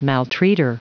Prononciation du mot maltreater en anglais (fichier audio)
Prononciation du mot : maltreater